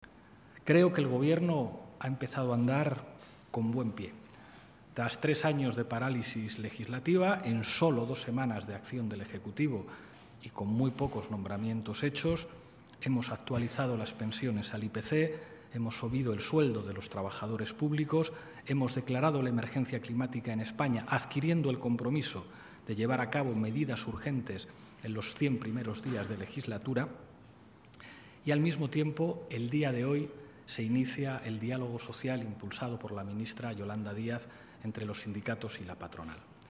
expresó también formato MP3 audio(0,56 MB) su sensación de que “el Gobierno ha empezado con buen pie”, felicitándose de la actividad e iniciativas del Ejecutivo en apenas un par de semanas, “tras tres años de parálisis”.